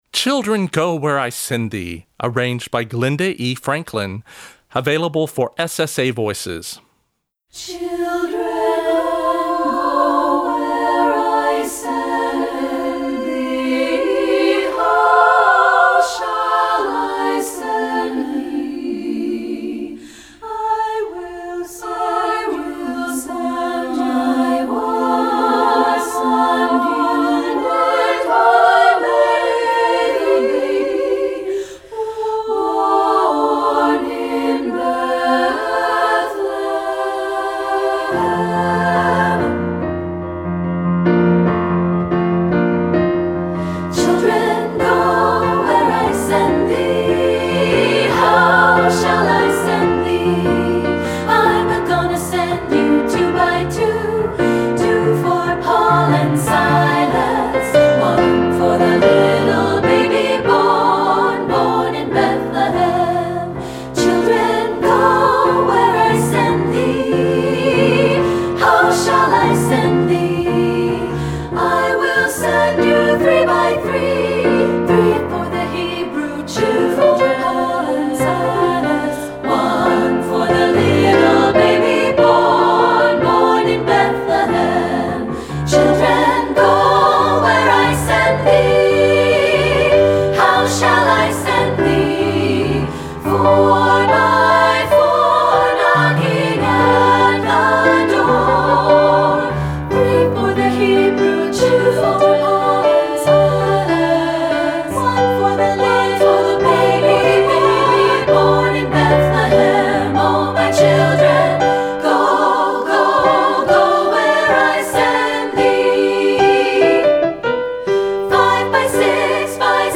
Composer: Spirituals
Voicing: SSA